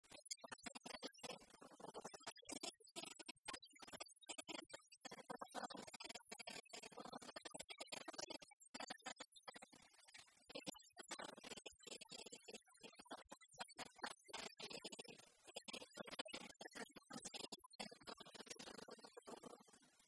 circonstance : fiançaille, noce
Pièce musicale inédite